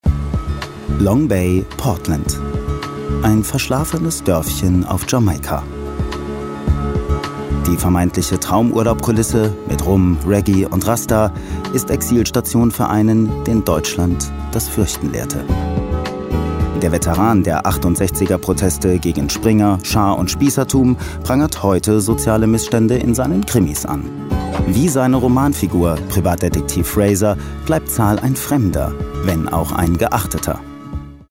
Sprecher Imagefilm, Dokumentarfilm, Off Sprecher, Voice over, Werbesprecher, Feature, Hörbuch, Hörspiel, Point of Sale, DMAX, Vodafone, Synchronsprecher, Sonore Stimme, Arte, 3SAT
Sprechprobe: Sonstiges (Muttersprache):